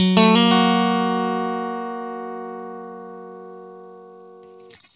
RIFF1-120FS.wav